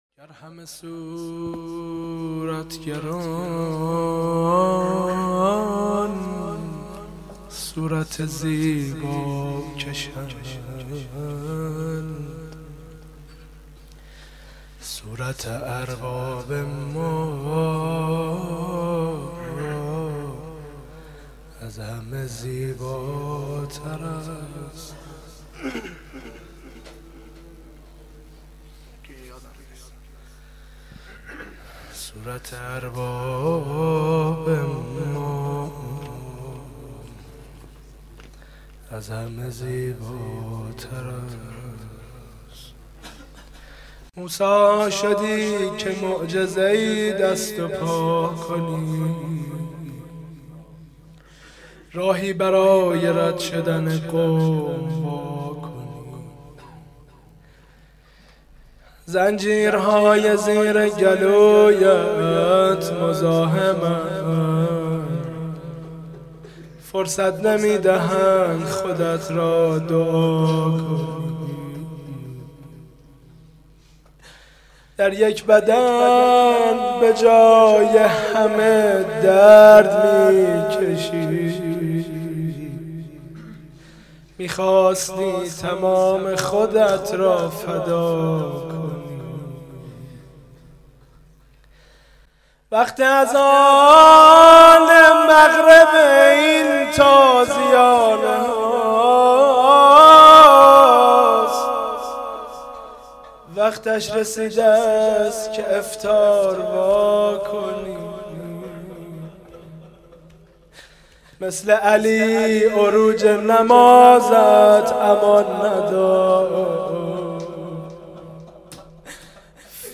روضه (توسل پایانی)
روضه‌ی خانگی